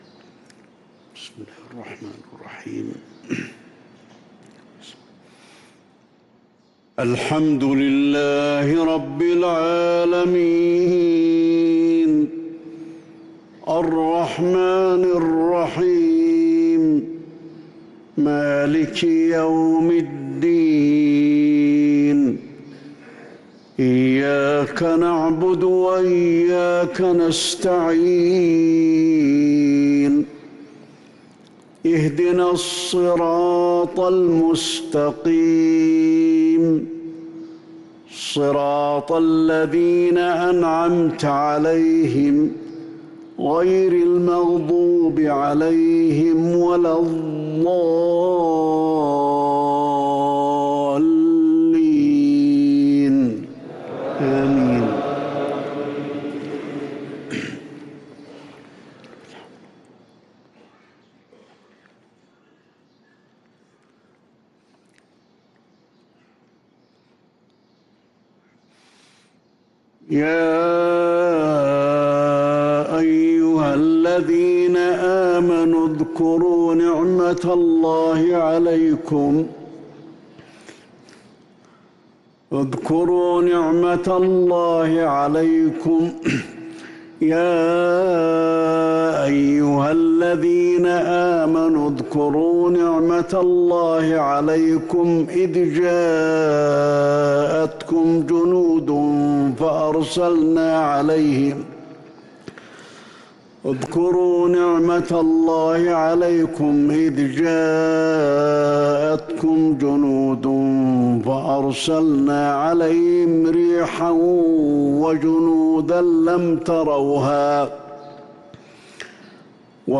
صلاة الفجر للقارئ علي الحذيفي 21 ربيع الآخر 1445 هـ
تِلَاوَات الْحَرَمَيْن .